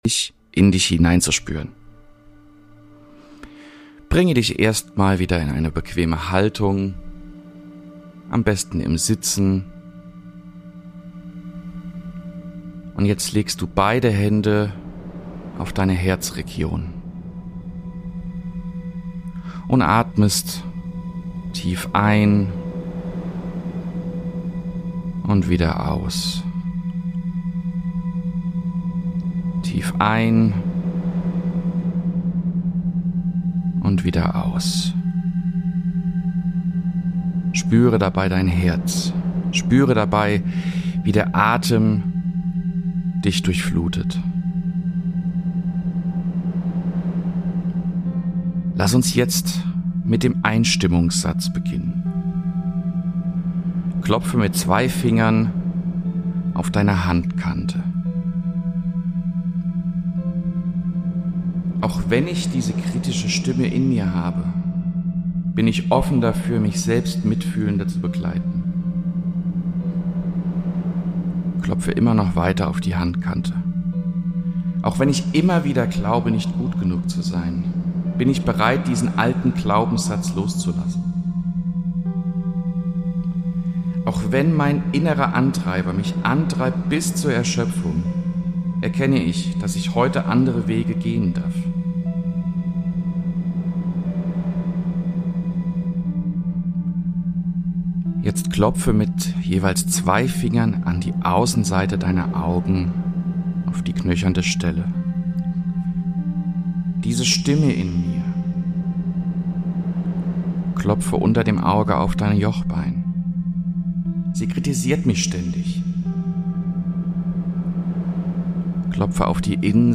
Geführte EFT-Routine bei Selbstkritik: Der innere Kritiker darf leiser werden